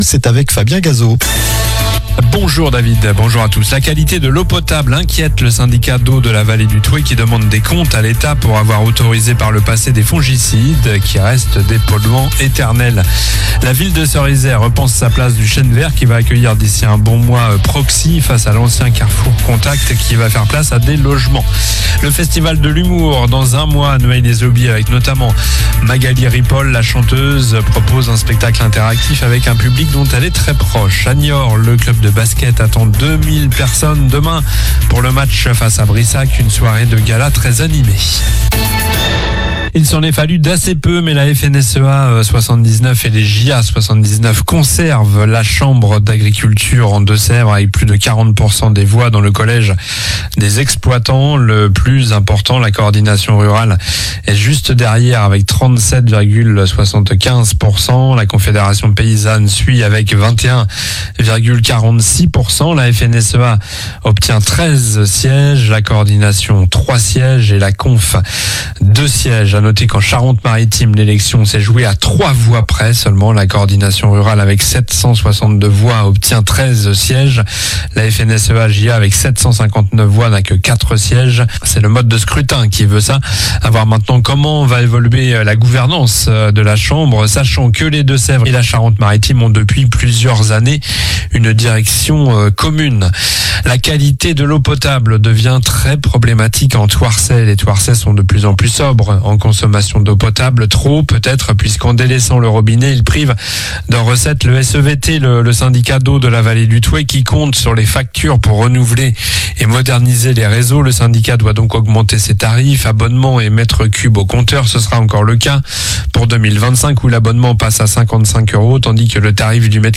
Journal du vendredi 07 février (midi)